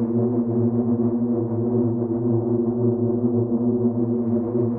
SS_CreepVoxLoopA-02.wav